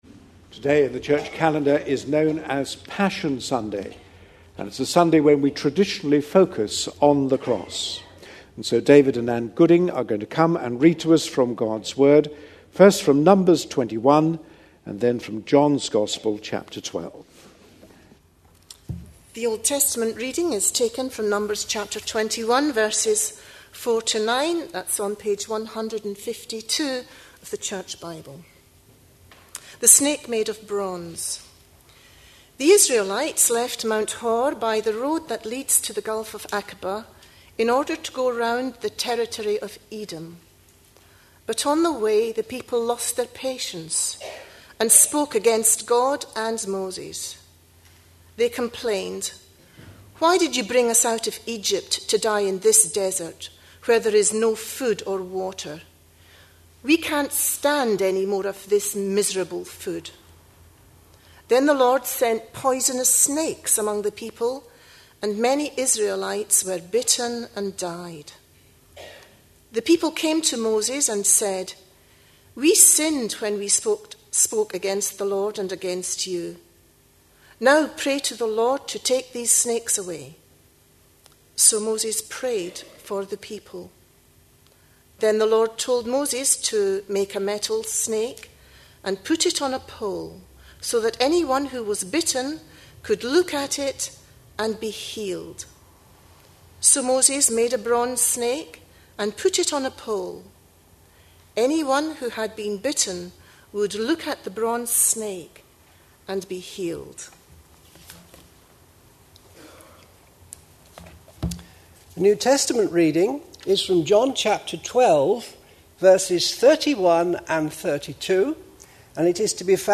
A sermon preached on 10th April, 2011, as part of our A Passion For.... series.
The service took place on Passion Sunday.